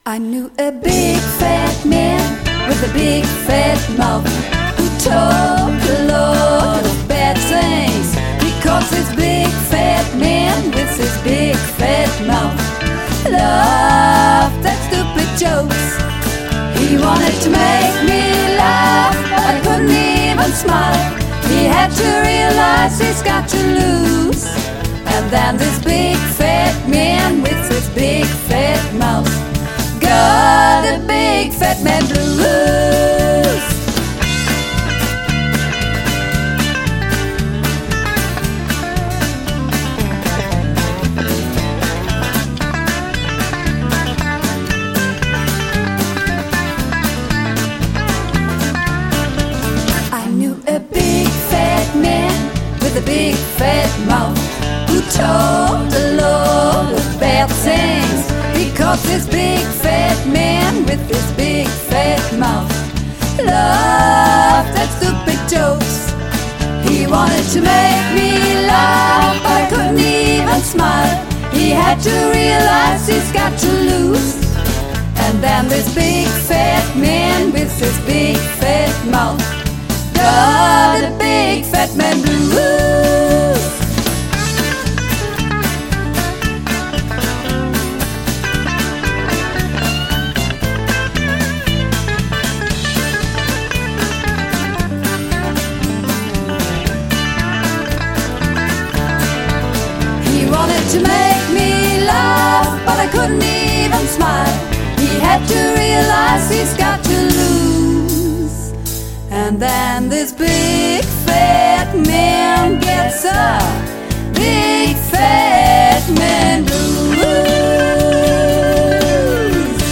Übungsaufnahmen - Big Fat Man
Big Fat Man (Mehrstimmig)
Big_Fat_Man__3_Mehrstimmig.mp3